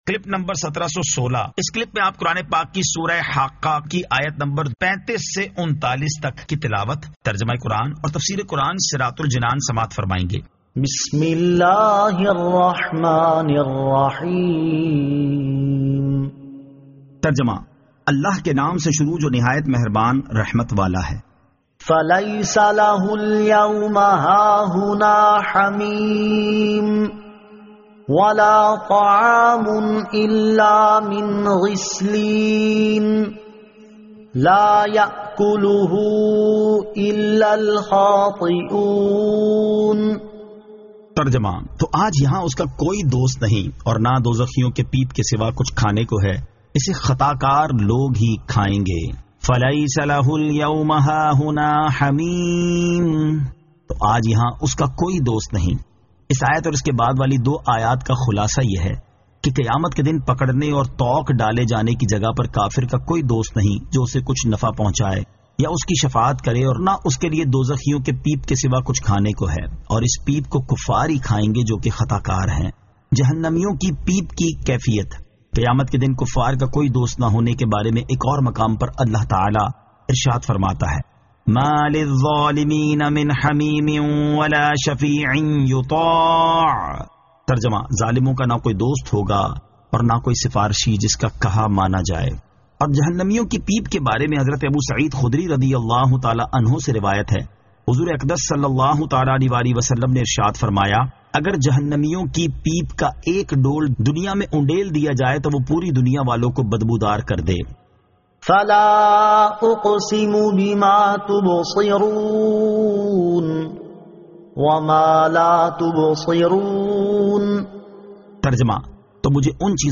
Surah Al-Haqqah 35 To 39 Tilawat , Tarjama , Tafseer